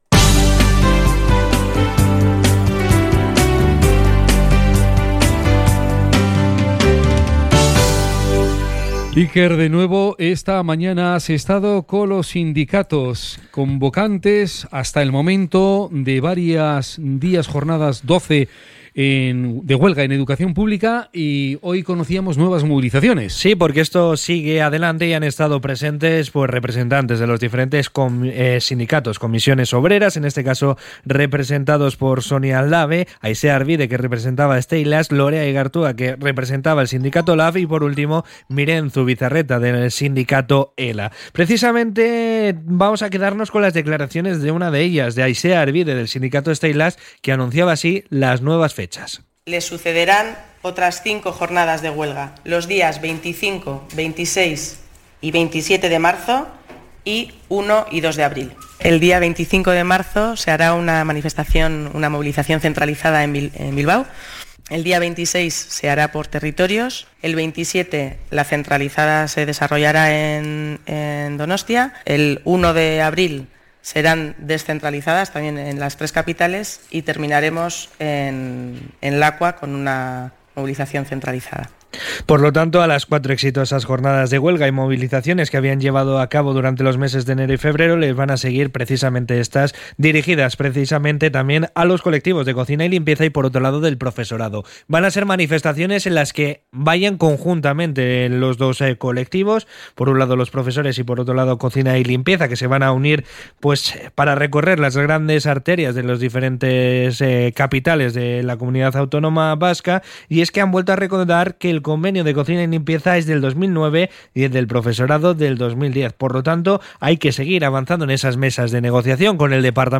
CRONICA-EDUCACION.mp3